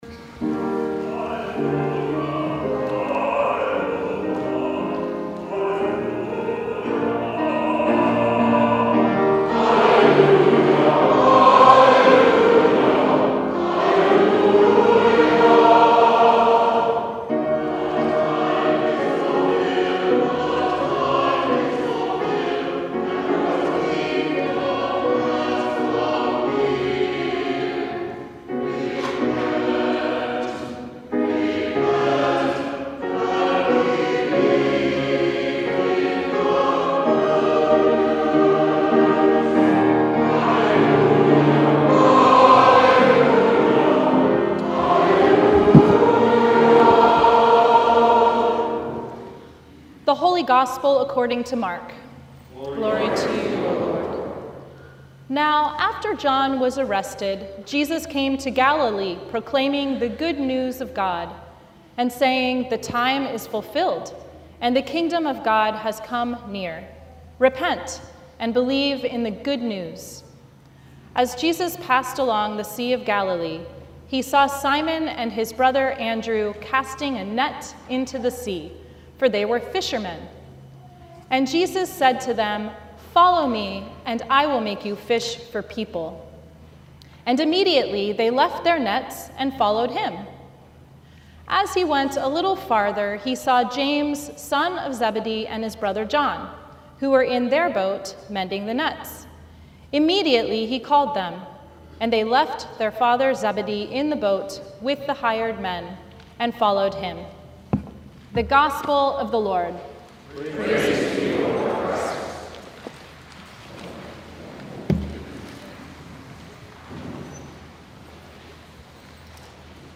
Sermon from Third Sunday After Epiphany